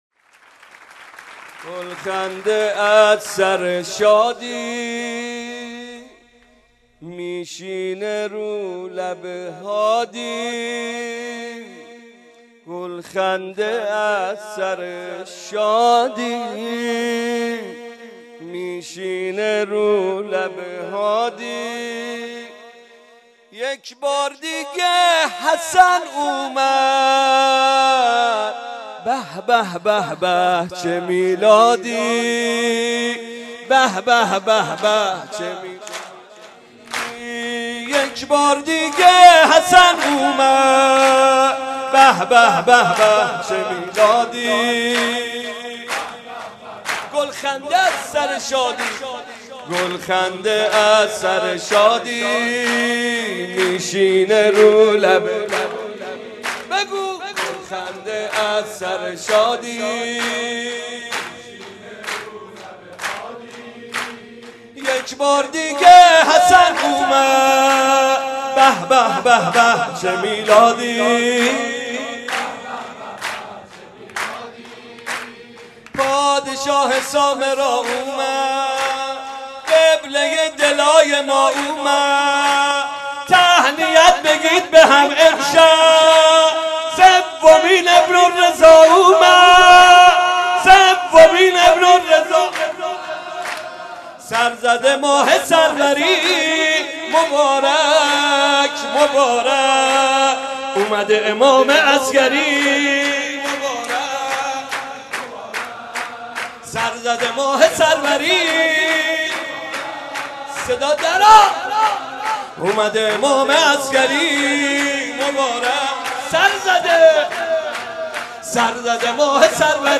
مولودی- گلخنده از سر شادی می شینه رو لب هادی